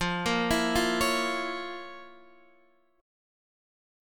FM7sus4#5 chord